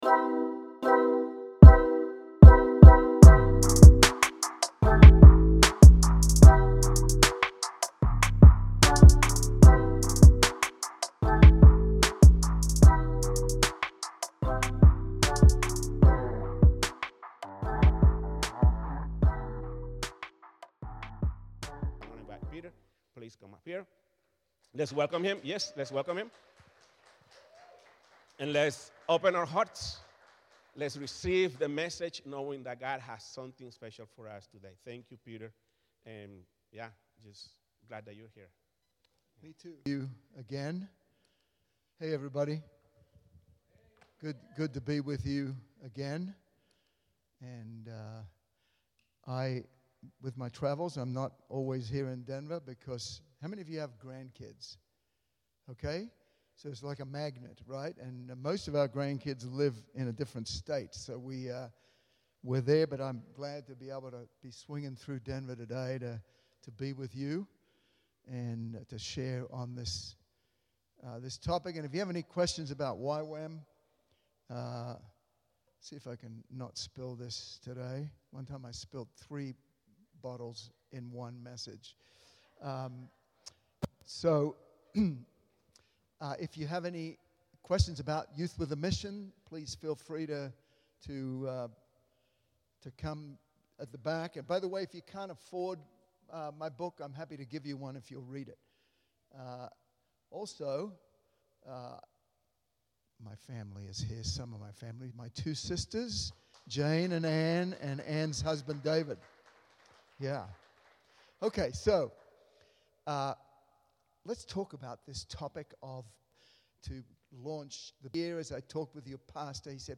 Prayer Panel
Service Type: Sunday Service